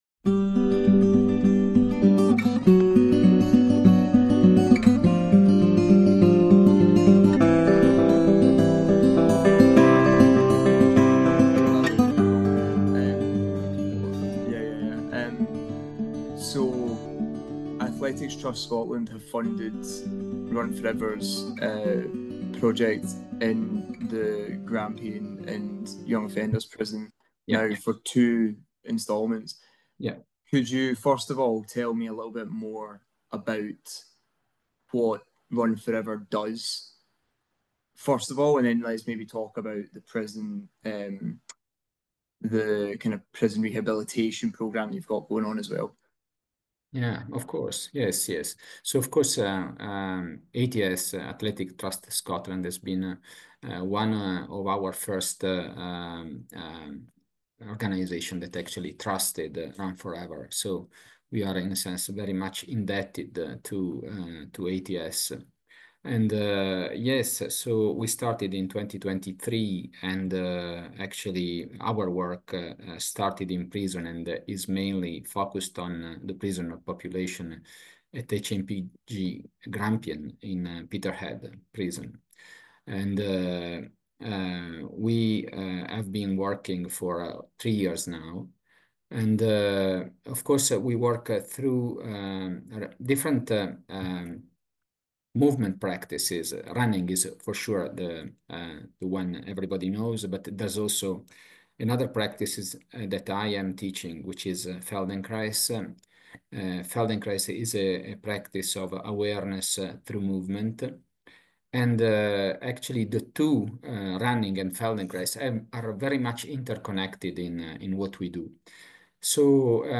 Interview – Conversation: